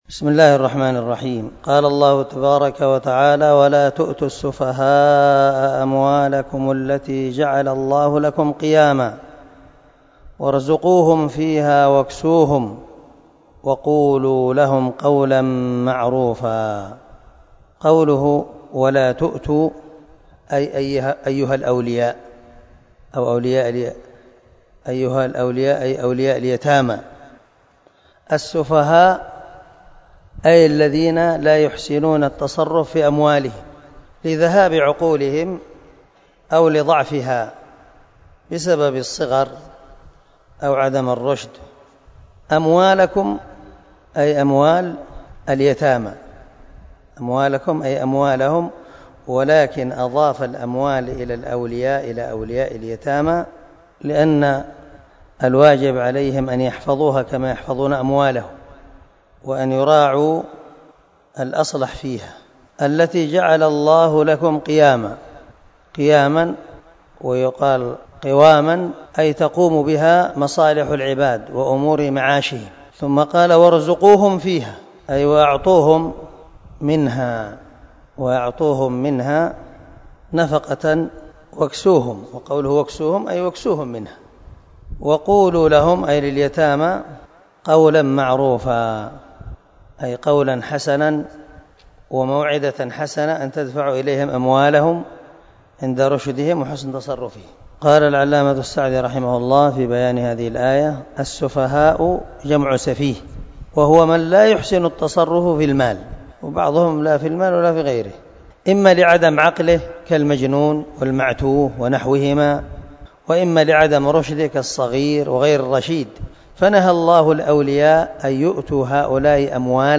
236الدرس 4 تفسير آية ( 5 ) من سورة النساء من تفسير القران الكريم مع قراءة لتفسير السعدي